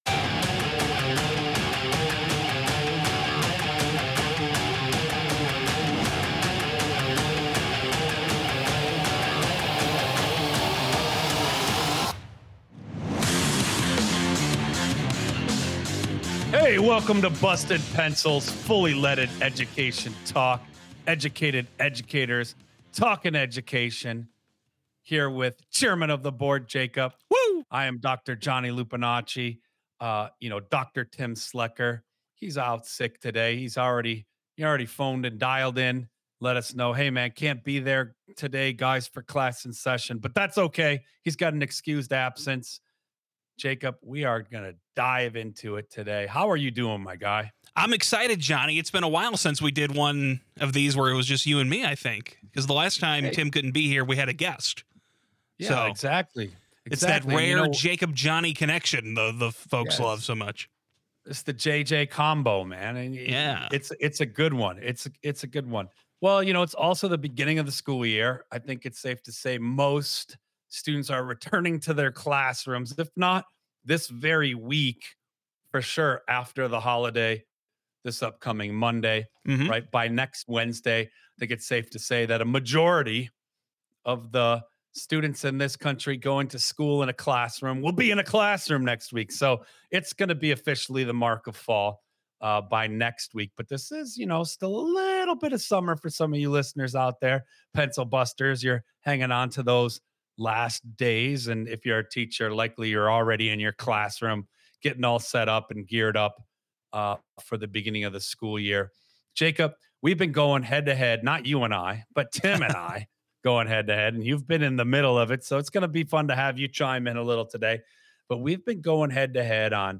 BustED Pencils: Fully Leaded Education Talk is part of Civic Media.